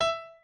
pianoadrib1_52.ogg